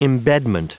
Prononciation du mot embedment en anglais (fichier audio)
Prononciation du mot : embedment